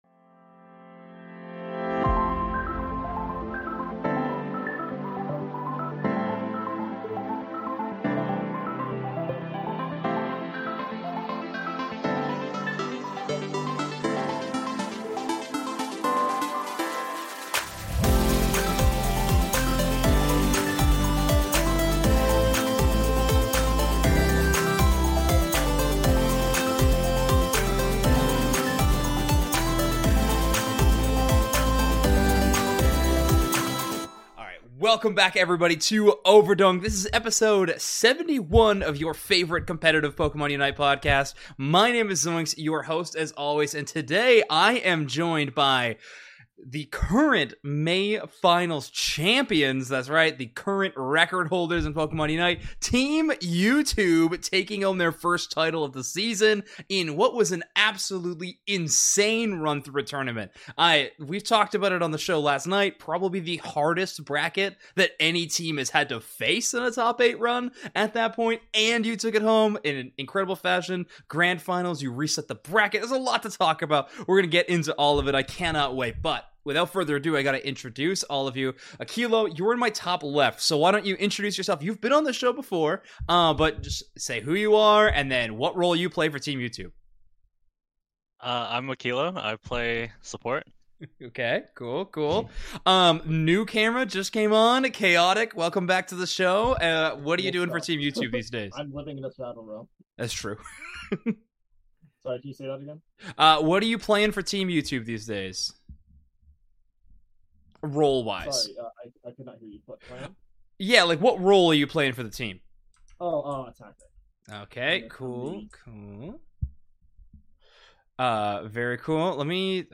Overdunk Ep.71 Team YouTube interview!